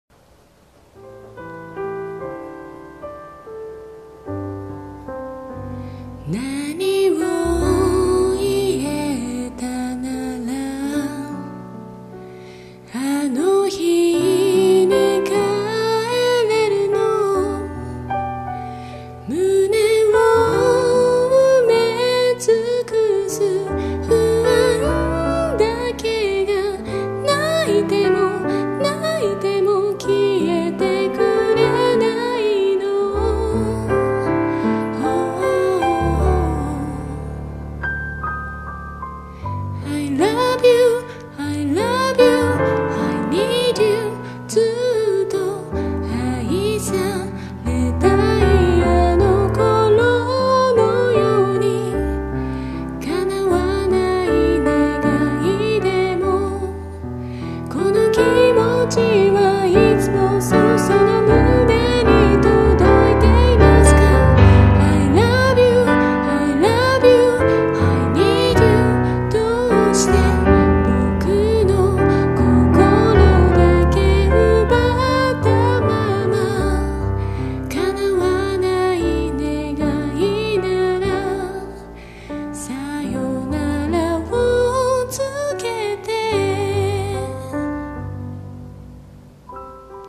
女性キー）を今すぐダウンロード＆リピート再生できます！